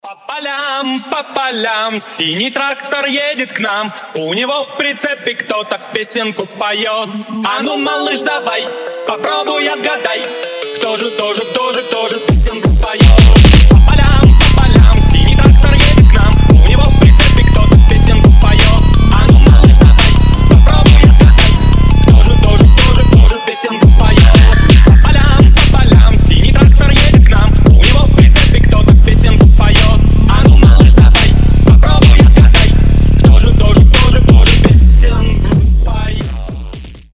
фонк ремикс